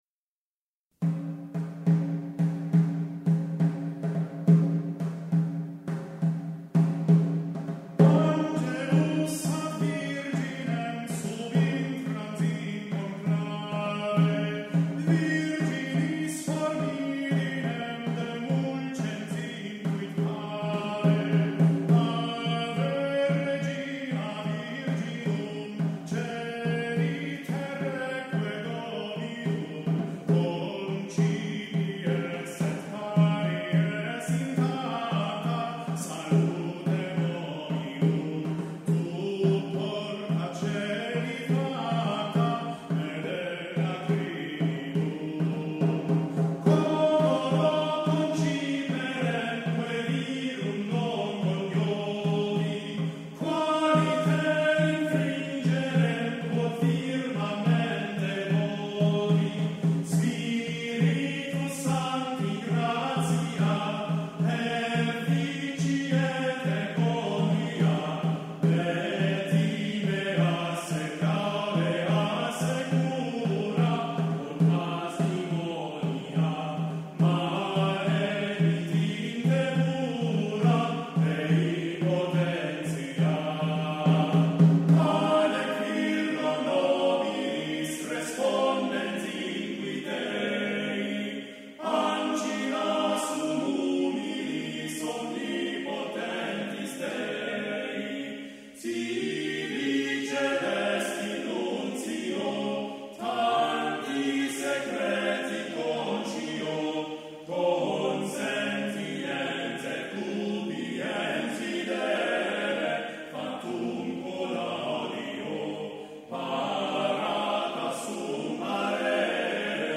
sacred music, traditional catholic church near me, organ music, choir, catholic choir, gregorian chant, catholic youth choir, catholic adult choir, twin cities, St. louis park, holy family choir, minnesota
Performance Samples